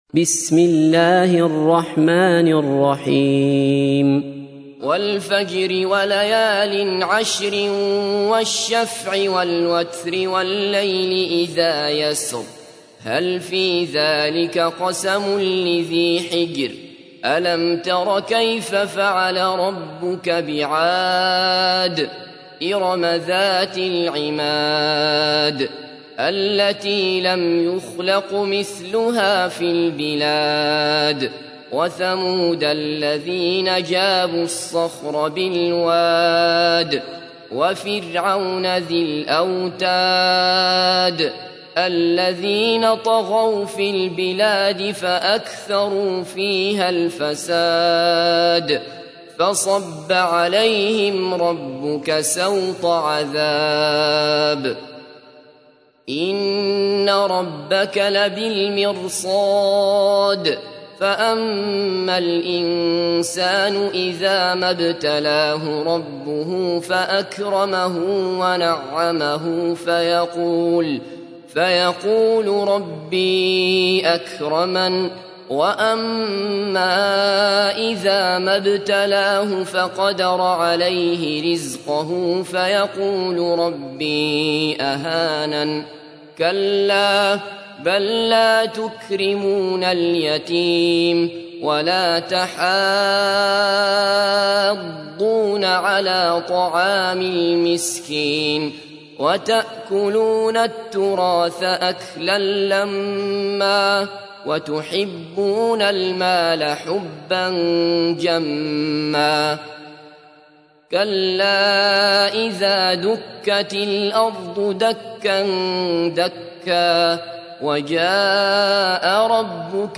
تحميل : 89. سورة الفجر / القارئ عبد الله بصفر / القرآن الكريم / موقع يا حسين